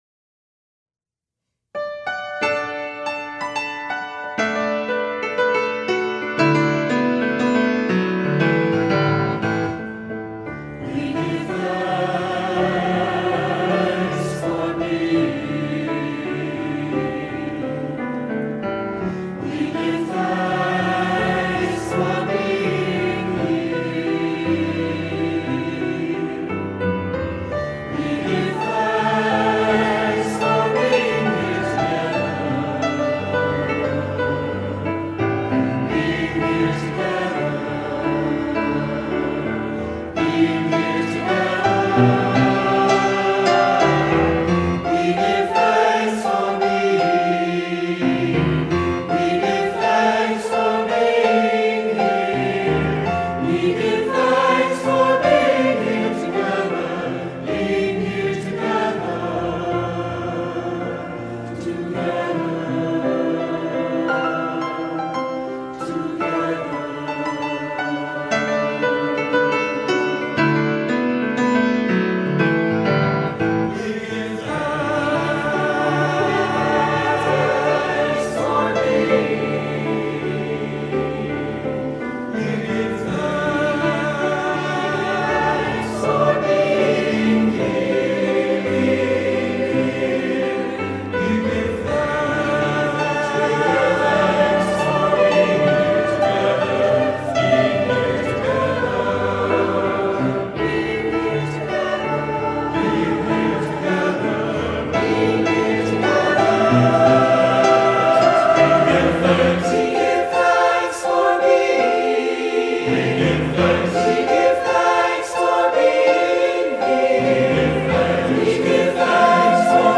Flexible choral voicing: 1-4 part choir, piano
An exuberant song of gratitude and joy for all ages.